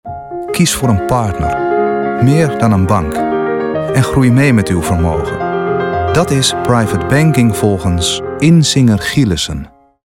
Voice actor